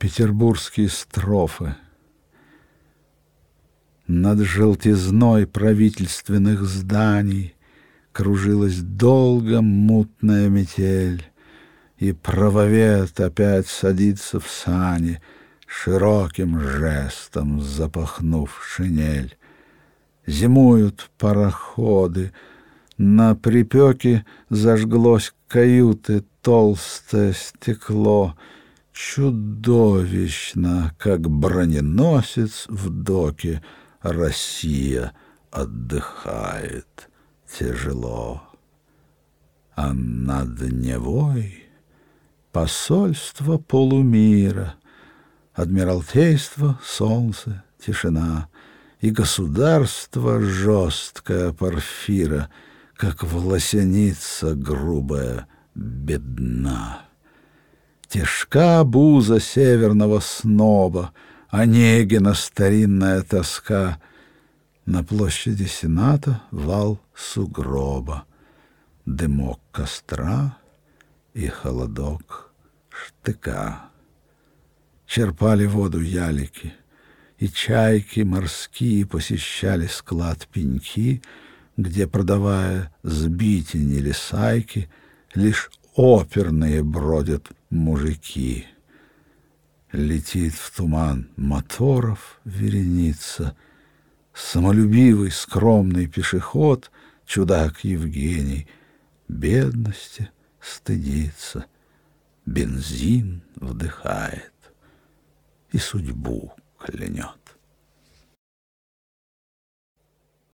4. «Сергей Юрский Авторский сборник “Концерт для – Осип Мандельштам – Петербургские строфы» /